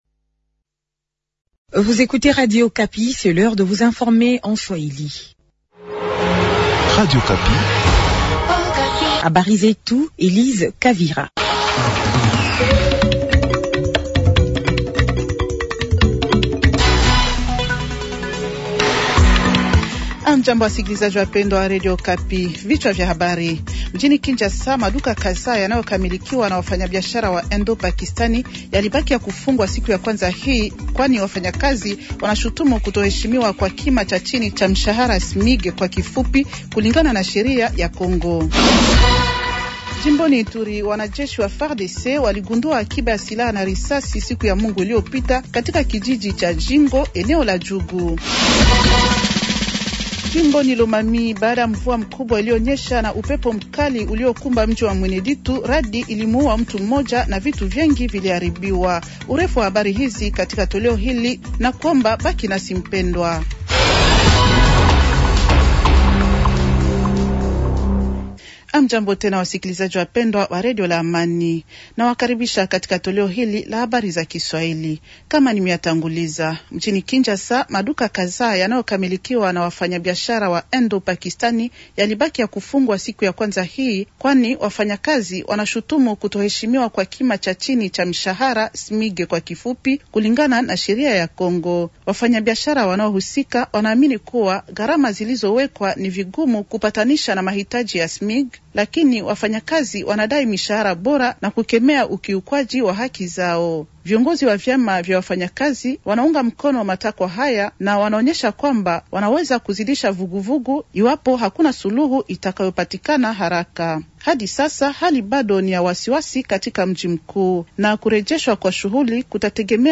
Journal swahili de lundi soir 230326